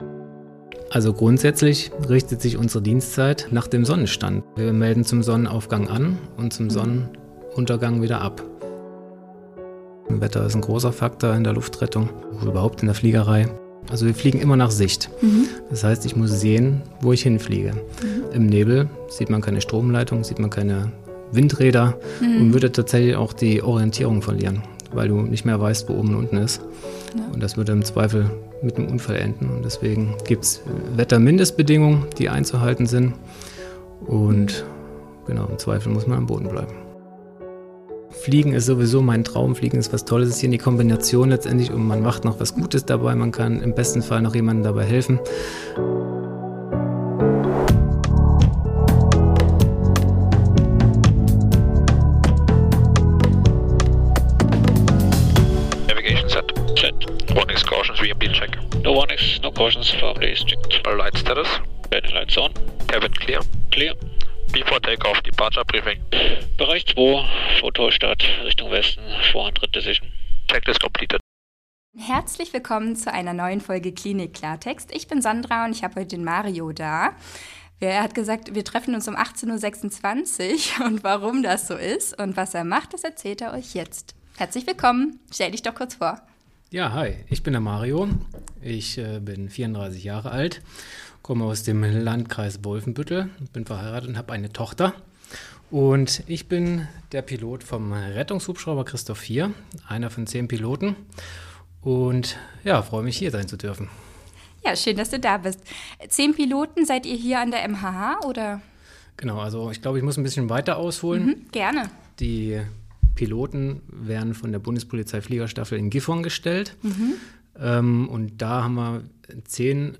Rettungshubschrauberpilot – Ein Interview